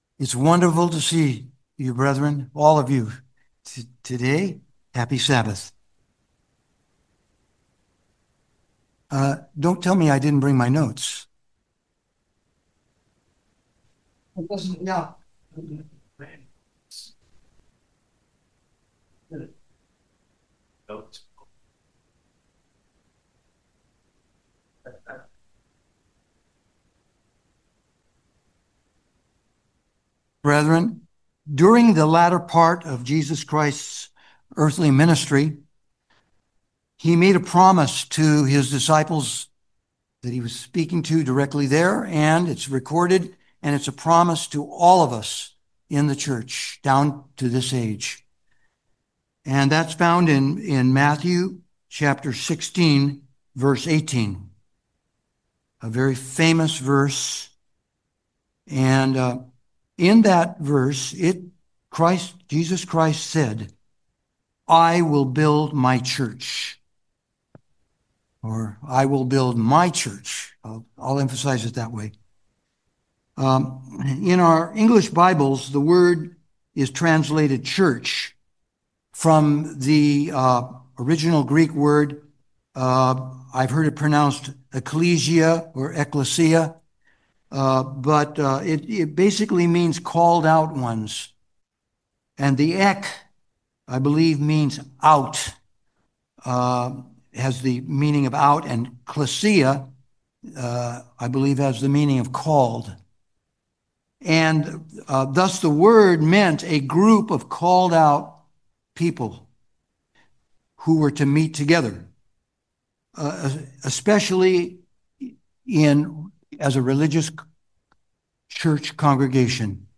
Sermons
Given in San Francisco Bay Area, CA Petaluma, CA San Jose, CA